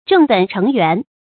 正本澄源 注音： ㄓㄥˋ ㄅㄣˇ ㄔㄥˊ ㄧㄨㄢˊ 讀音讀法： 意思解釋： 猶正本清源。